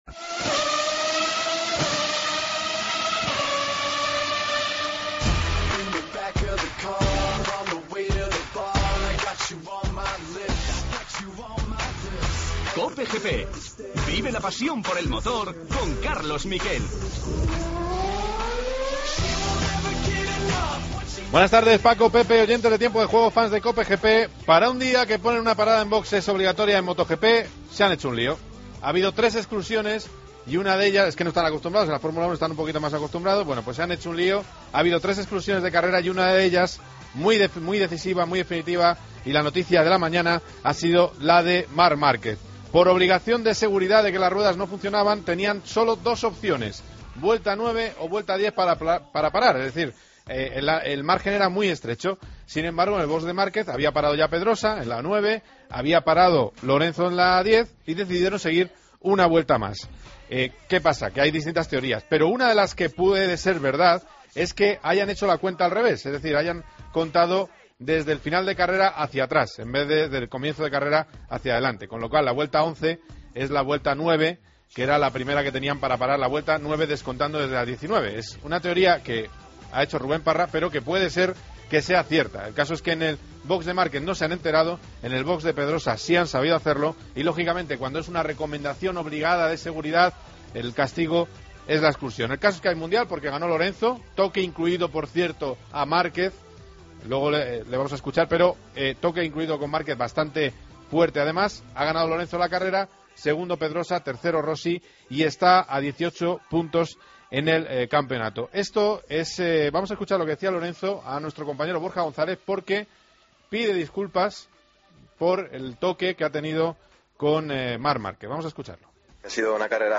Entrevista al piloto español.